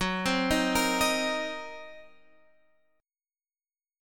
F#6b5 chord